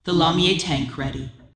Subject description: Perhaps an extremely rare basic main battle tank with a female voice   Reply with quote  Mark this post and the followings unread
I am not a woman, this is using AI technology to replace my vocals with AI tones.